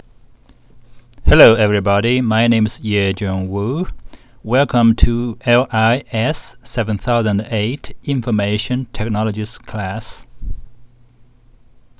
2. An audio greeting in your own voice. You need a computer microphone for recording your voice.